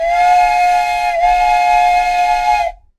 Train Whistle
A classic steam train whistle blowing with rich harmonics and distance fade
train-whistle.mp3